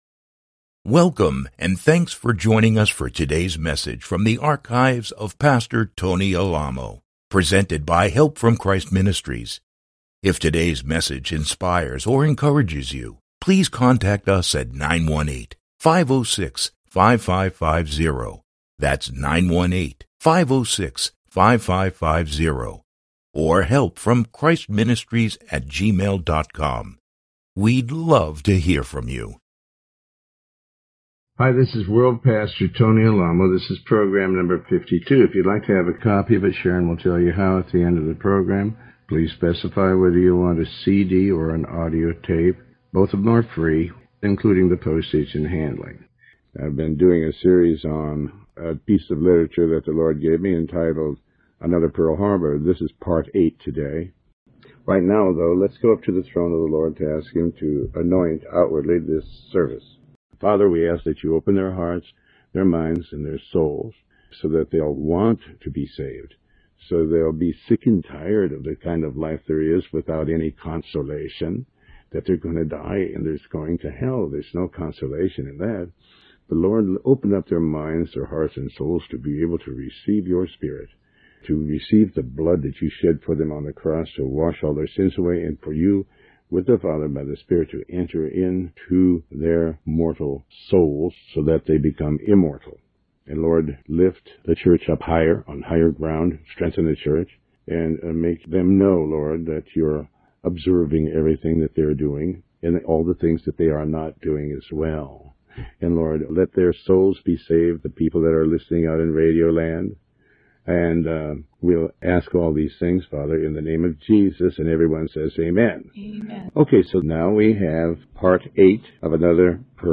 Sermon 52B